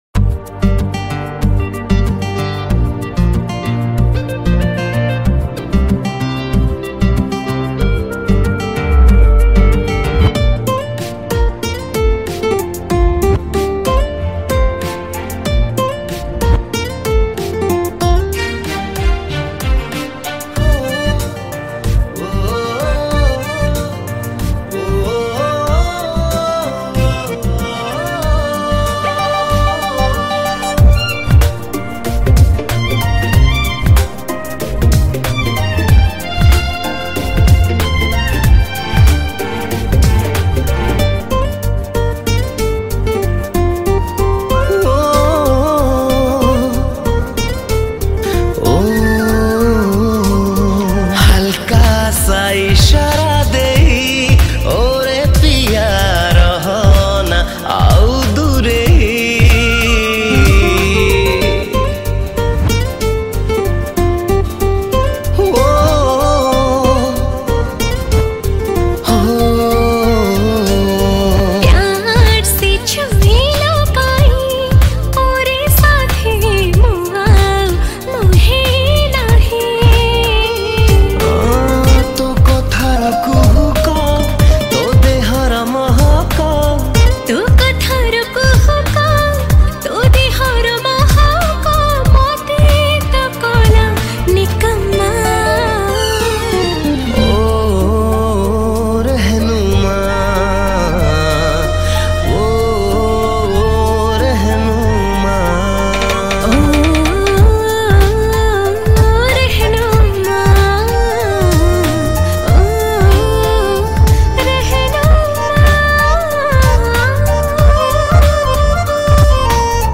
Romantic Song